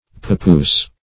Papoose \Pa*poose"\, n.